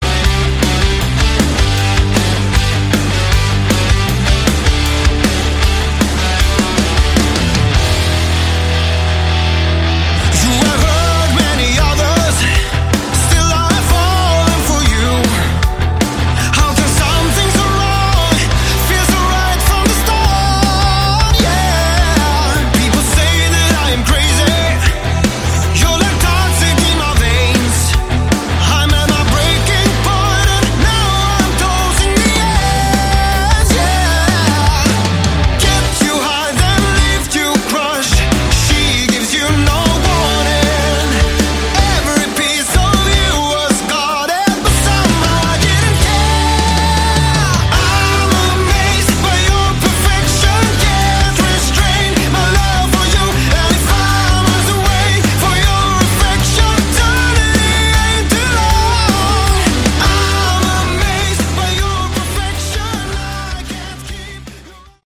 Category: AOR
keyboards, vocals
guitar, bass
drums